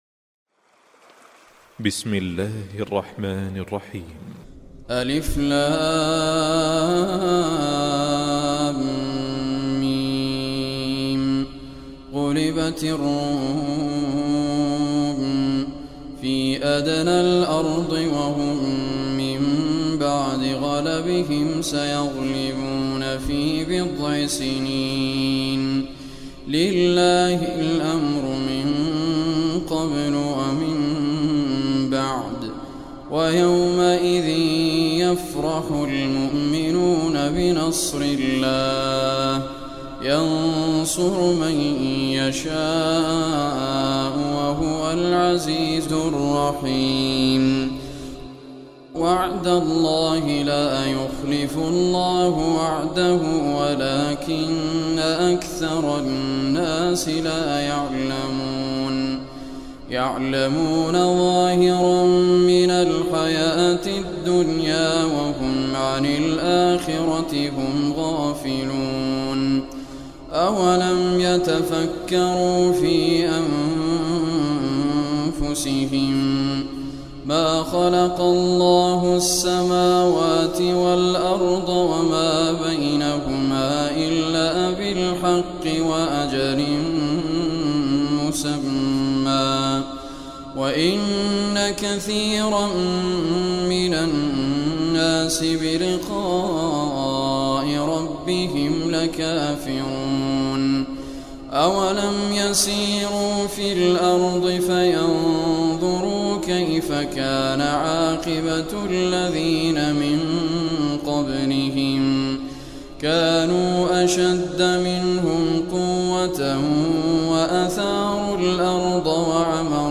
Surah ar Rum Recitation